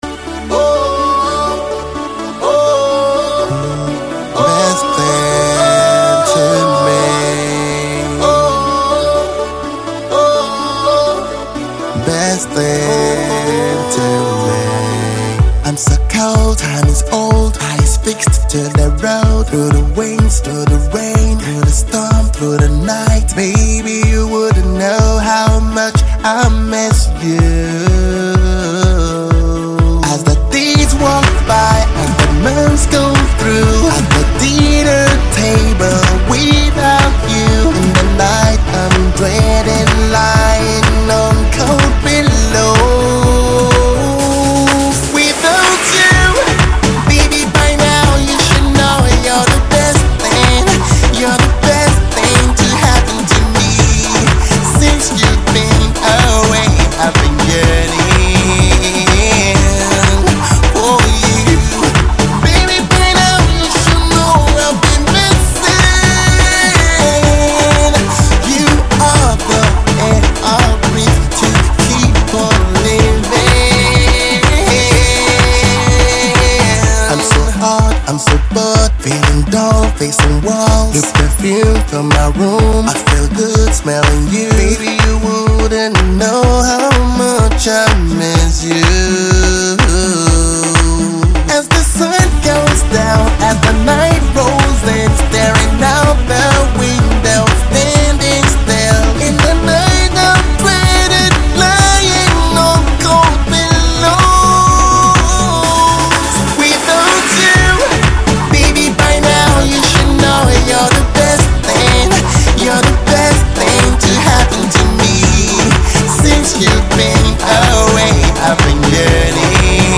R&B crooner